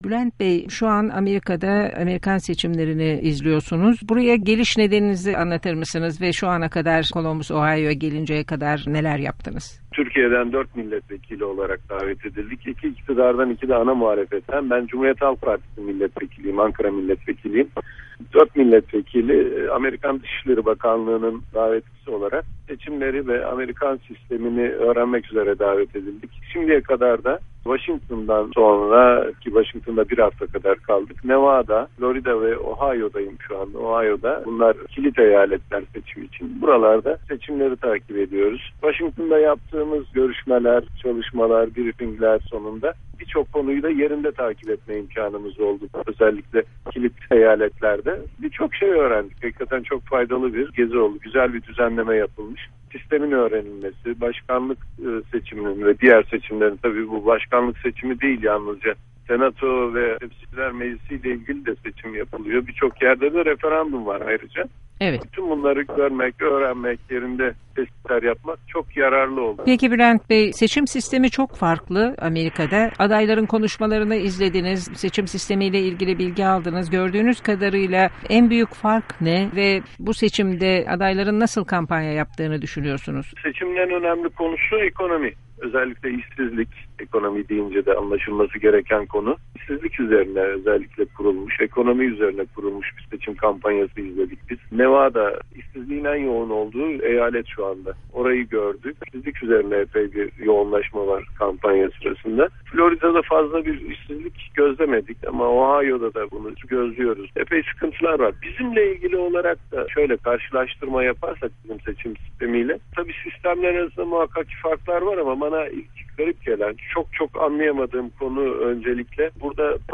Bülent Kuşoğlu ile Söyleşi